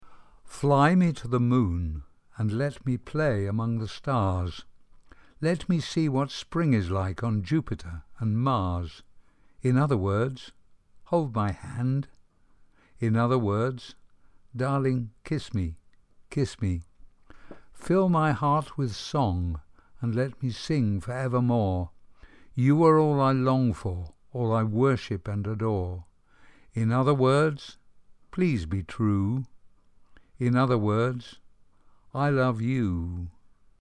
Fichiers de prononciation